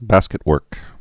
(băskĭt-wûrk)